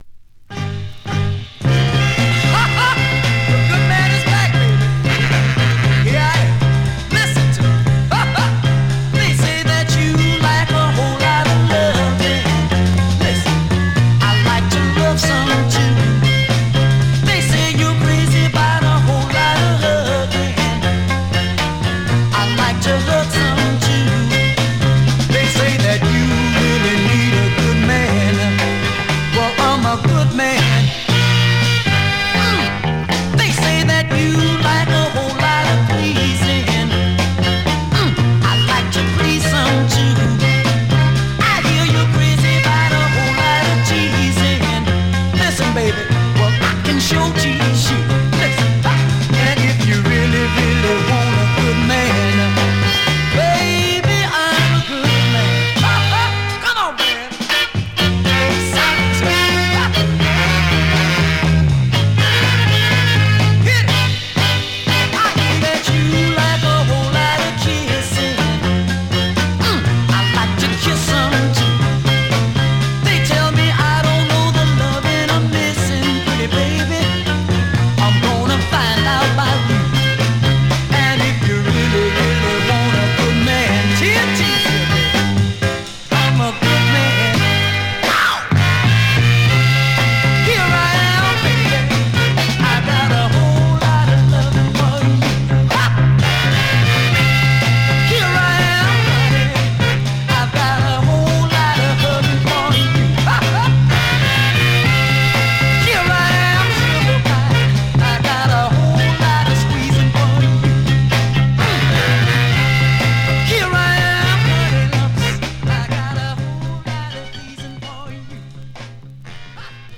Great mid-tempo dancer